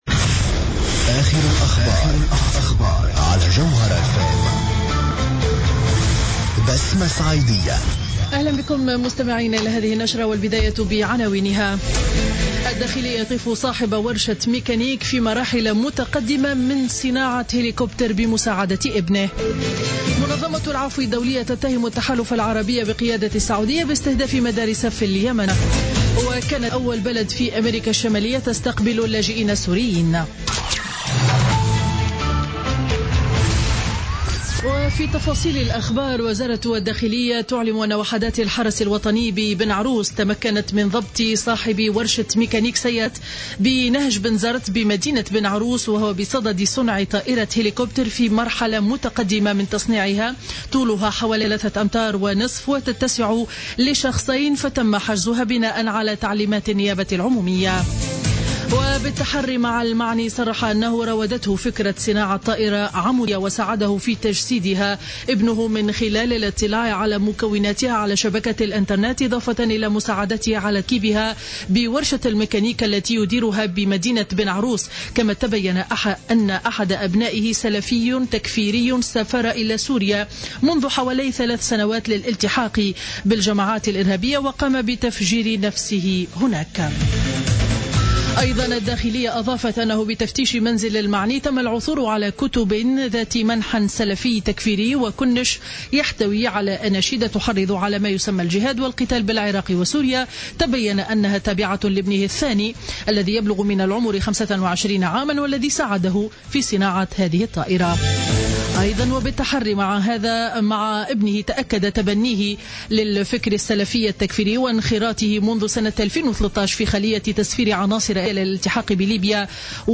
نشرة الأخبار منتصف النهار ليوم الجمعة 11 ديسمبر 2015